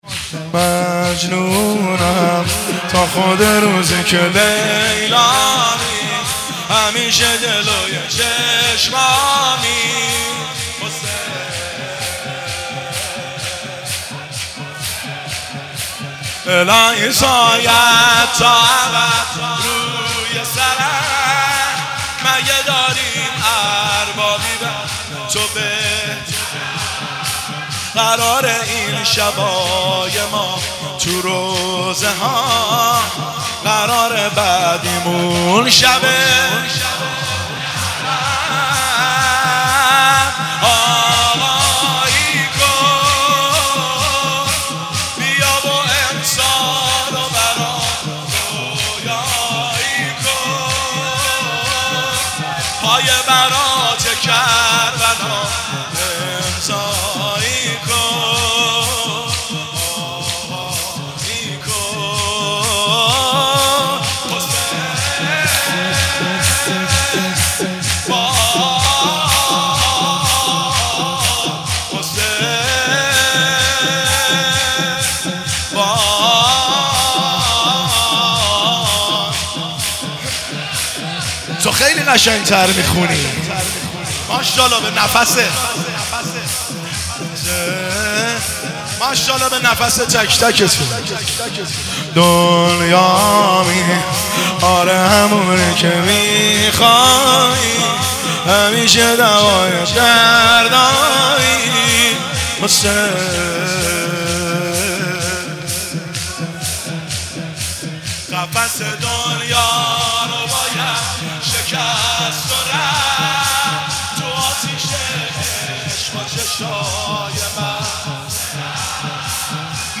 مجنونم تا خود روزی که لیلامی همیشه جلوی چشمامی حسین - شور